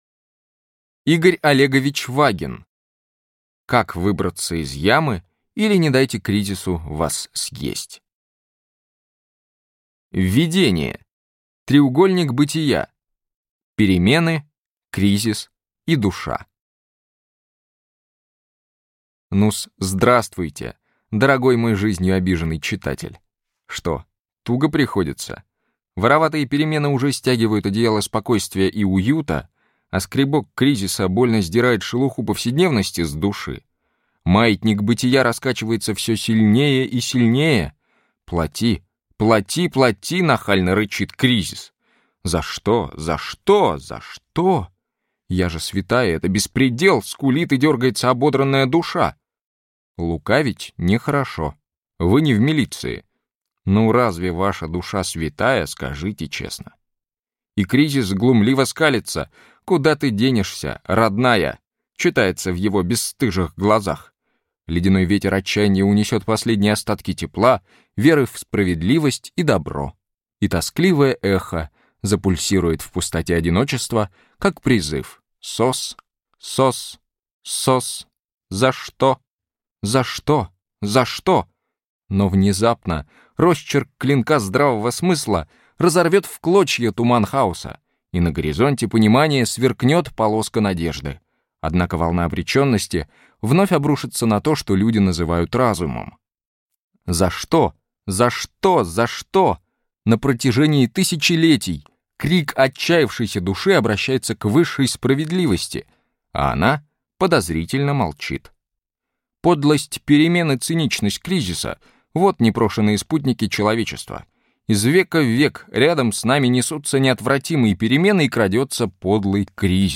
Аудиокнига Как выбраться из ямы, или Не дайте кризису вас съесть!